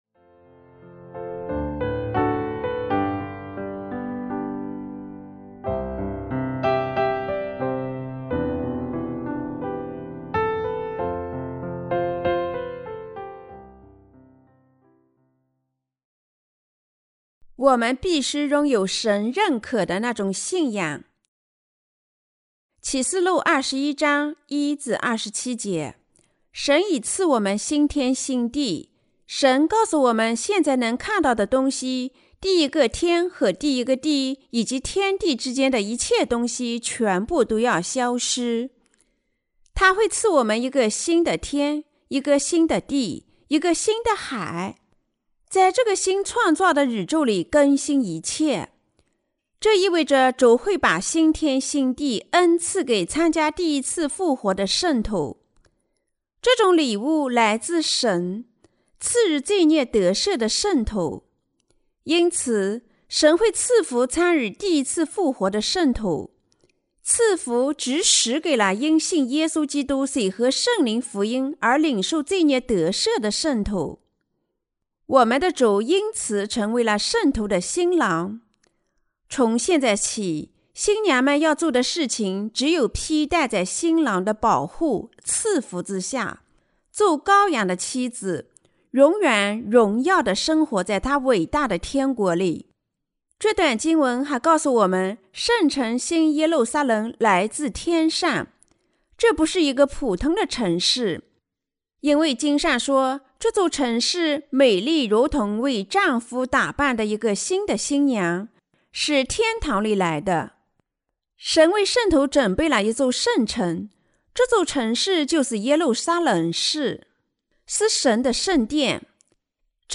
關於《啟示錄》的注釋和佈道 - 敵基督者、殉難、被提和千禧年王國的時代來臨了嗎？